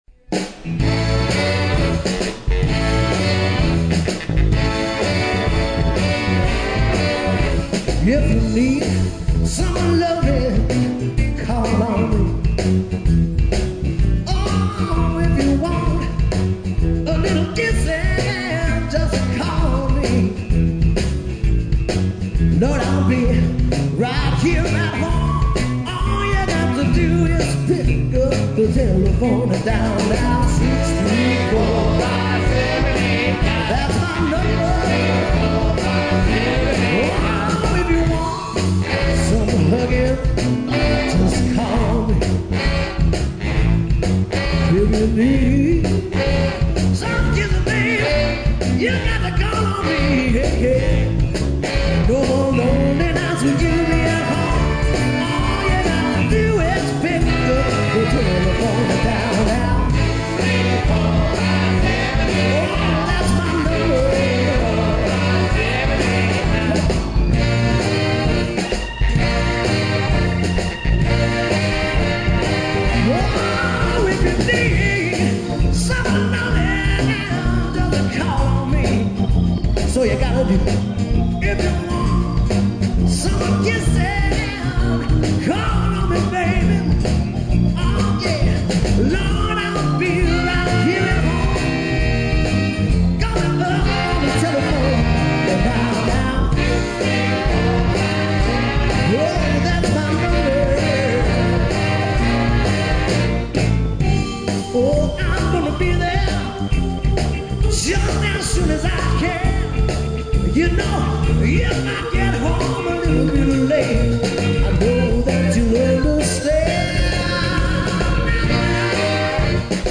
R&B, Soul, and Funk<
Roger Sherman Baldwin Park  - Greenwich, CT
( 2 trk live recording)